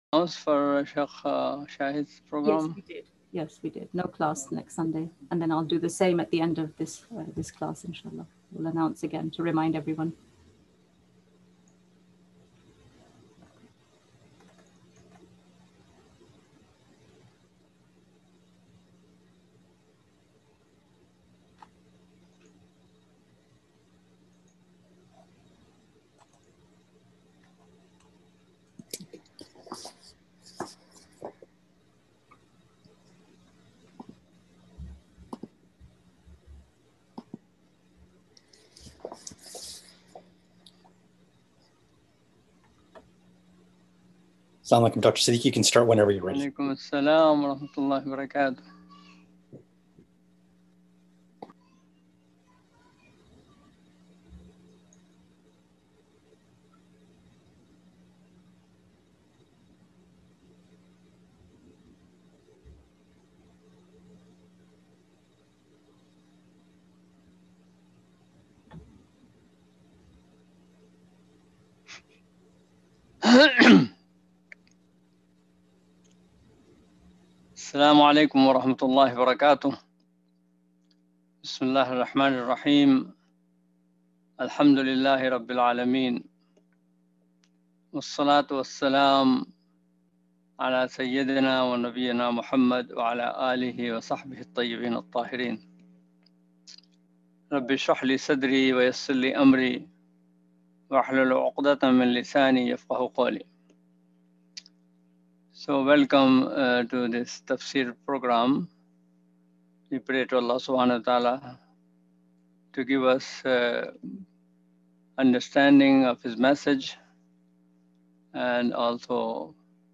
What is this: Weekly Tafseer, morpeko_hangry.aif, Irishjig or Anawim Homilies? Weekly Tafseer